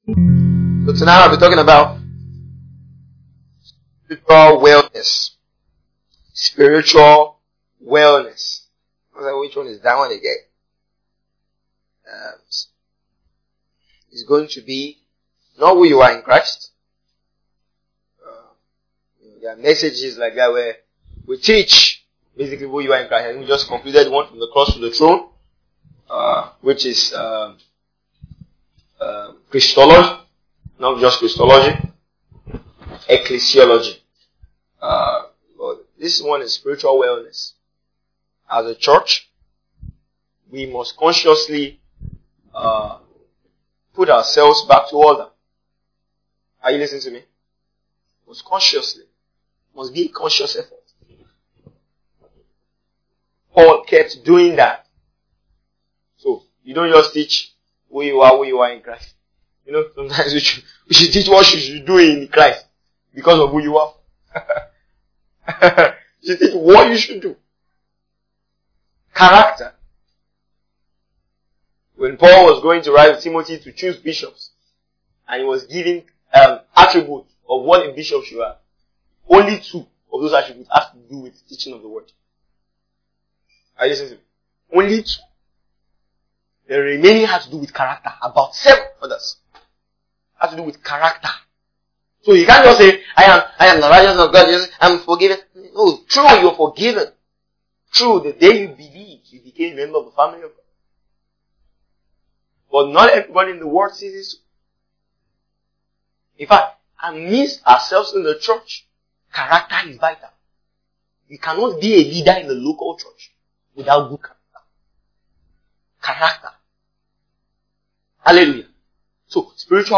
A teaching that puts the believer at the cutting edge of staying spiritually sane. It highlights a couple of things the believer must keep checking to know if he is spiritually well; Listen to this track to gauge how spiritually well you are.